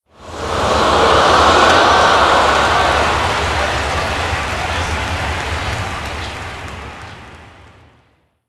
rr3-assets/files/.depot/audio/sfx/ambience/ambience_crowd_aghast.wav
ambience_crowd_aghast.wav